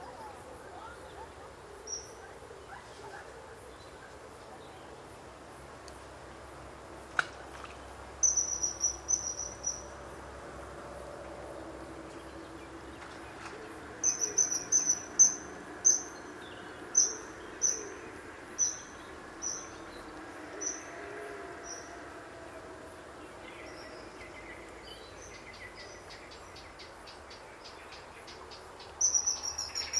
Martin-pêcheur d'Europe - Mes zoazos
martin-pecheur.mp3